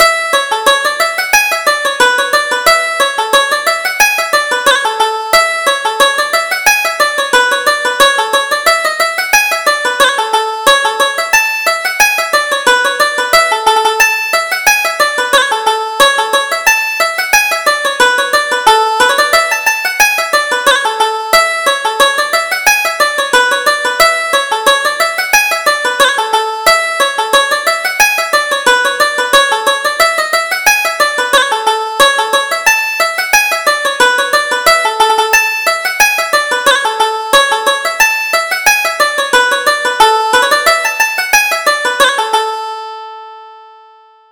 Reel: The Skibbereen Lasses